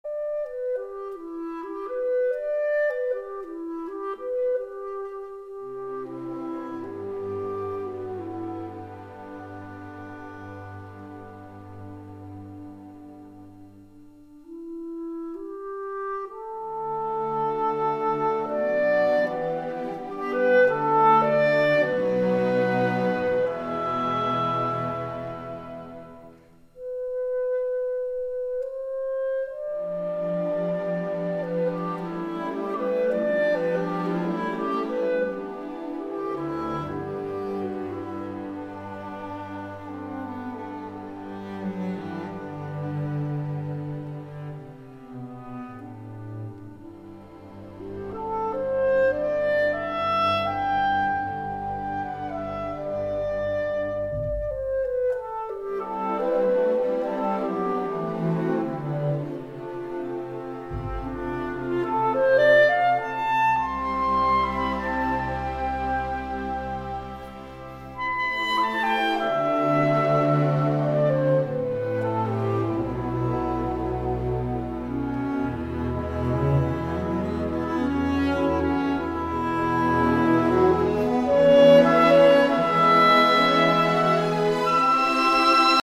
Жанр: Классическая музыка